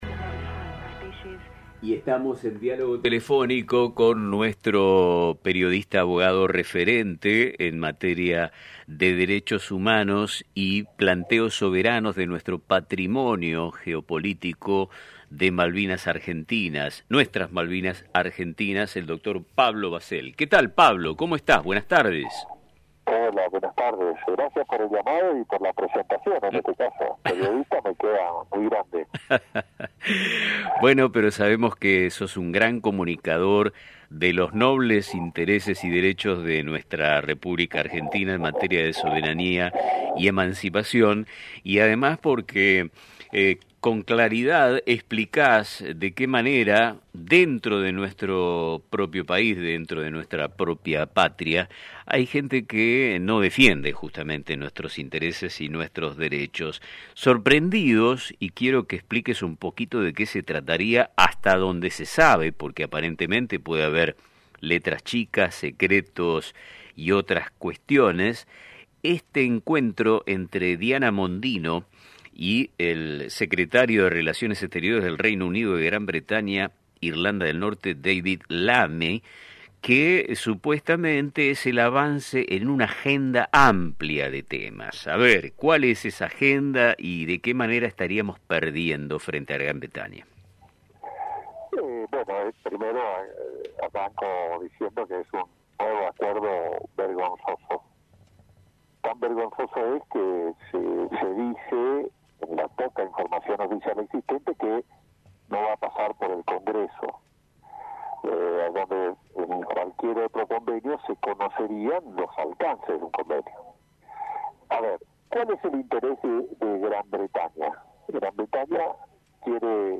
En una reciente entrevista en el programa radial *Nuestro Tiempo*